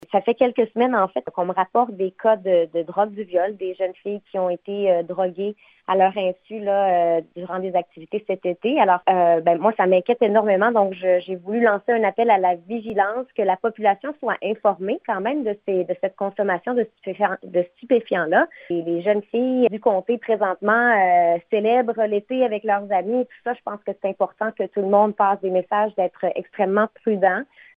La députée de Gaspé veut ainsi sensibiliser la population à la présence de cette drogue qui est généralement consommée à l’insu des victimes :